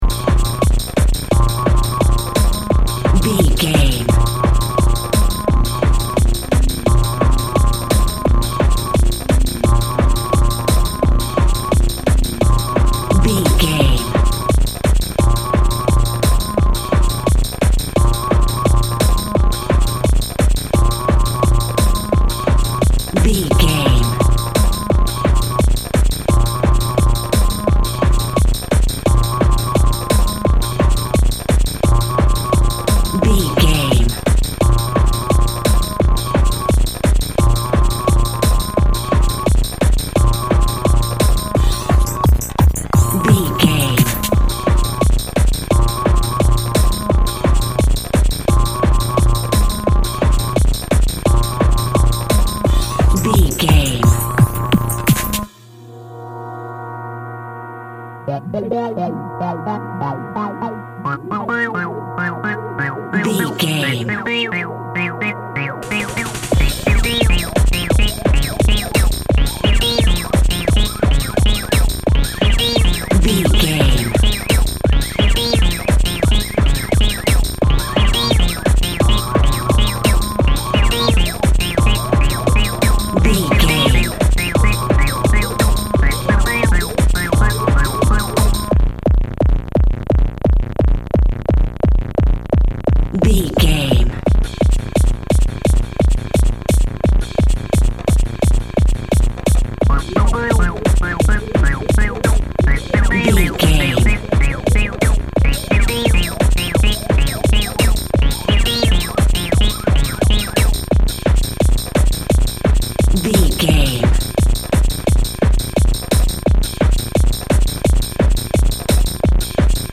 Fast paced
Phrygian
B♭
Fast
futuristic
frantic
driving
energetic
hard
synthesiser
drum machine
electronic
synth lead
synth bass